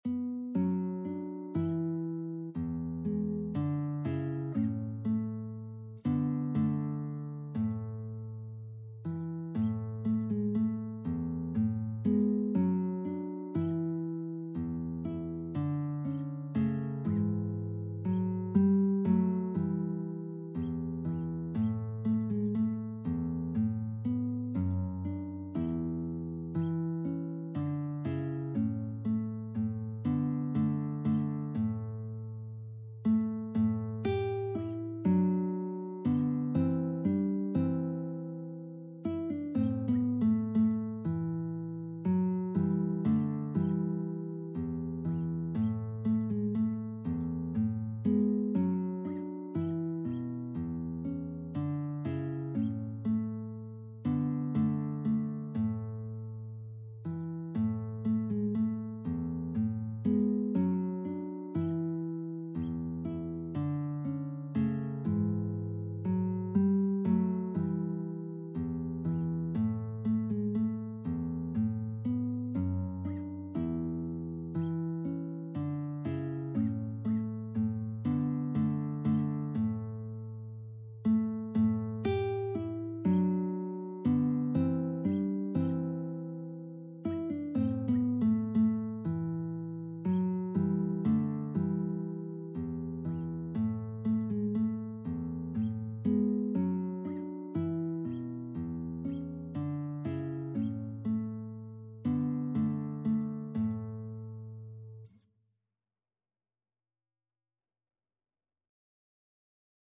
3/4 (View more 3/4 Music)
A major (Sounding Pitch) (View more A major Music for Guitar )
Slow, expressive =c.60
Guitar  (View more Intermediate Guitar Music)
Classical (View more Classical Guitar Music)